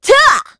Isolet-Vox_Attack3_kr.wav